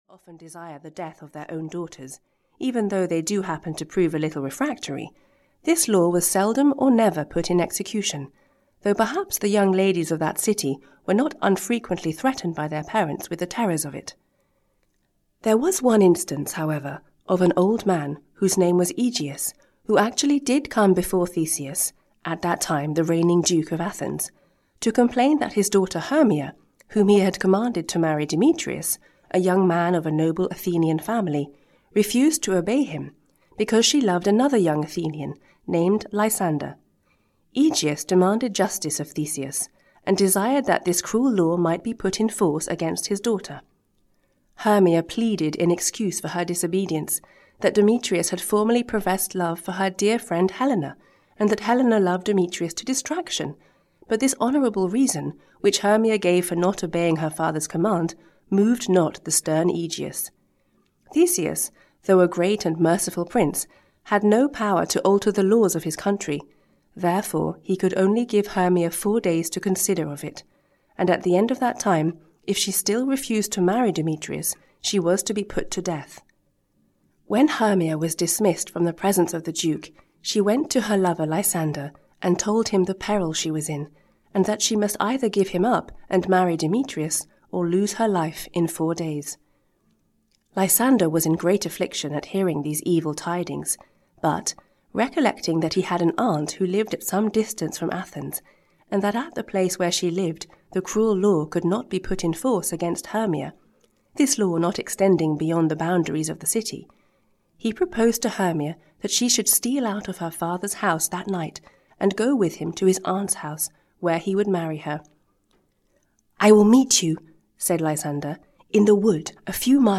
Audio knihaA Midsummer Night's Dream by William Shakespeare – Summary (EN)
Ukázka z knihy